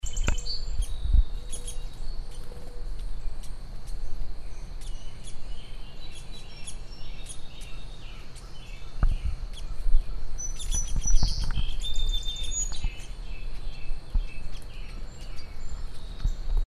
This youngster has been glued to its dad for the last day or so. The young bird follows where-ever the older bird goes, landing right next to him and begging:
Fledgling Downy Woodpecker This youngster has been glued to its dad for the last day or so.